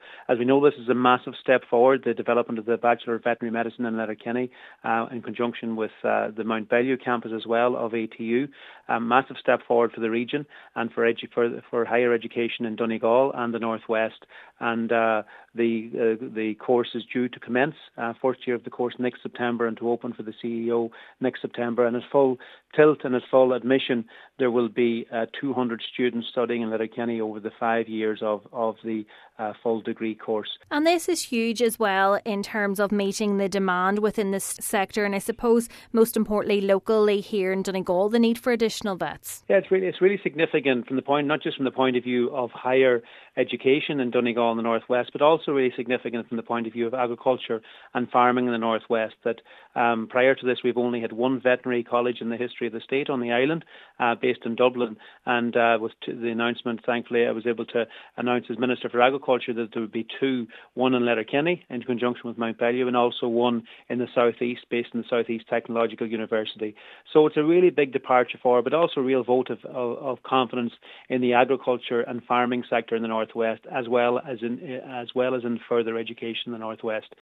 Donegal Minister Charlie McConalogue says this investment shows a vote of confidence in agriculture and farming in the North West: